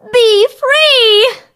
bea_ulti_vo_01.ogg